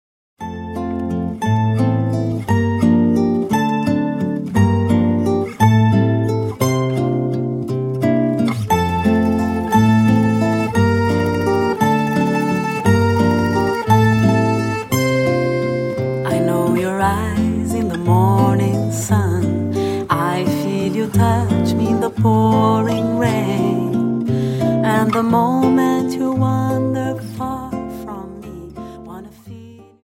Dance: Viennese Waltz 58 Song